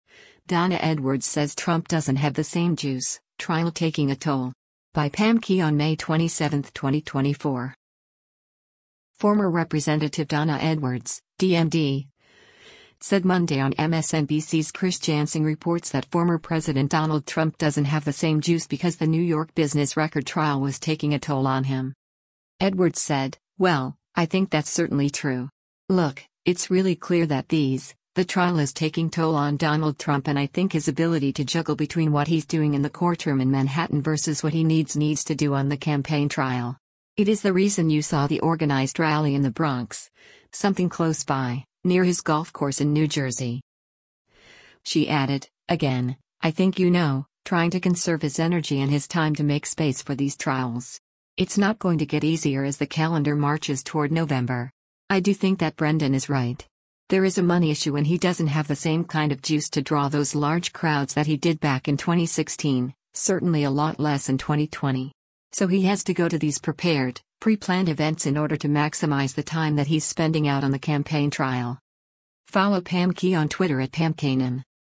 Former Rep. Donna Edwards (D-MD) said Monday on MSNBC’s “Chris Jansing Reports” that former President Donald Trump doesn’t have the same ‘”juice” because the New York business record trial was taking a toll on him.